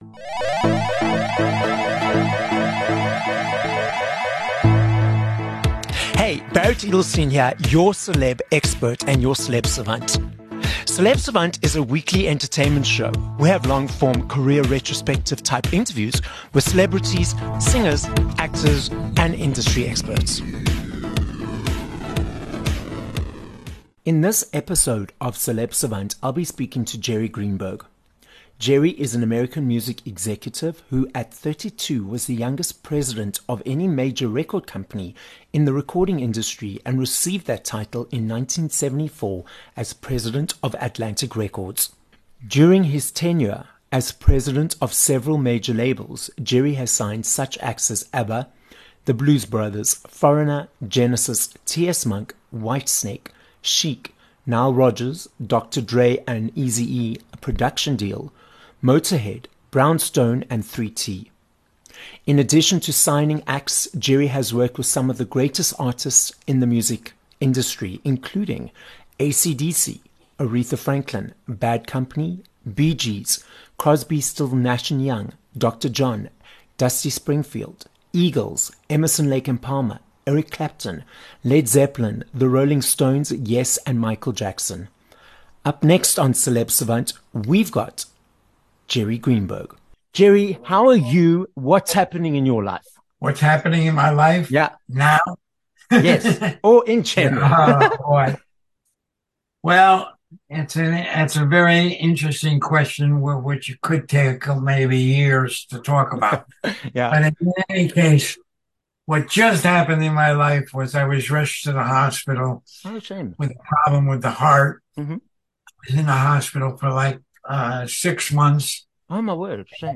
28 Feb Interview with Jerry Greenberg (Music Executive)